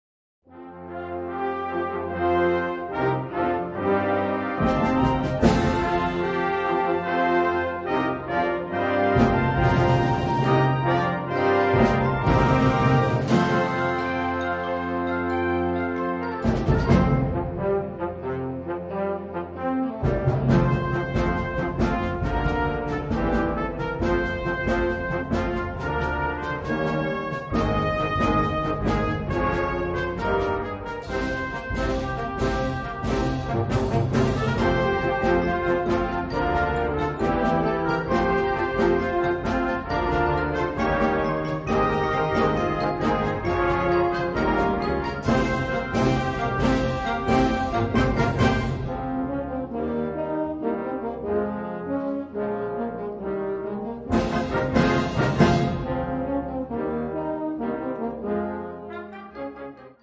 Gattung: Ouvertüre
3:40 Minuten Besetzung: Blasorchester Tonprobe